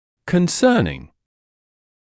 [kən’sɜːnɪŋ][кэн’сёːнин]относительно, касательно; инговая форма от to concern